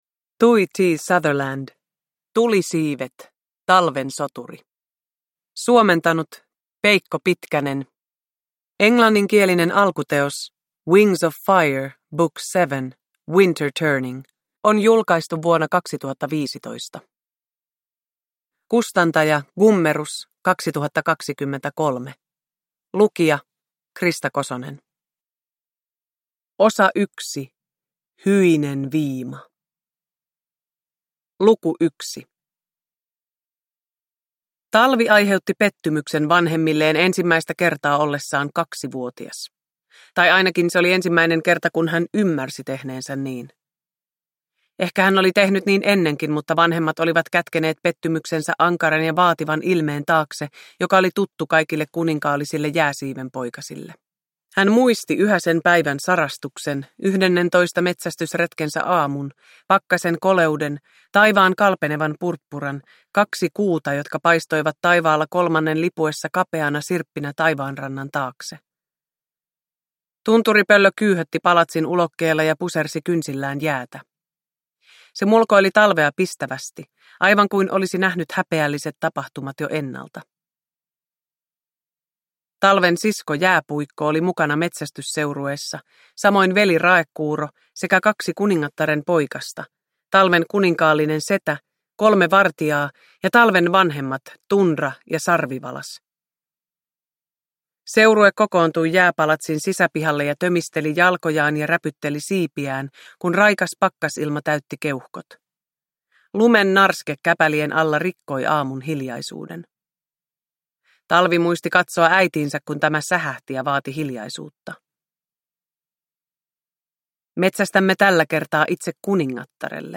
Talven soturi – Ljudbok
Uppläsare: Krista Kosonen